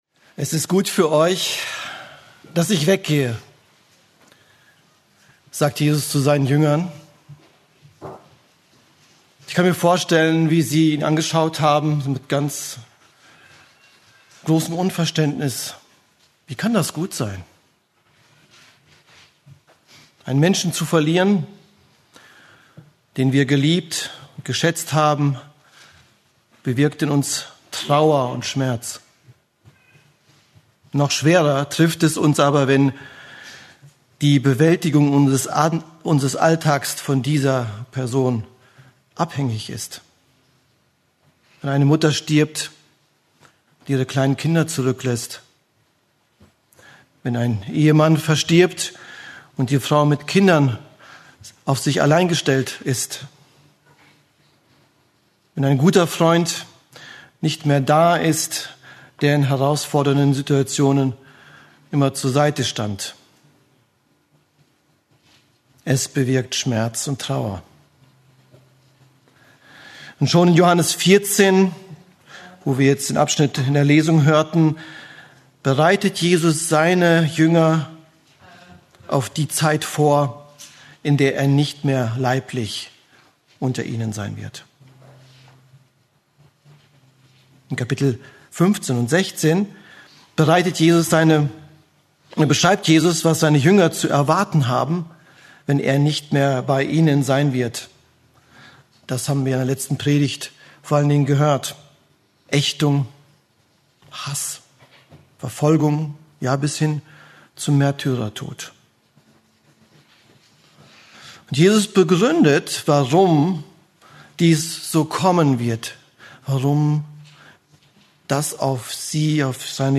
Eine predigt aus der serie "Johannes Evangelium."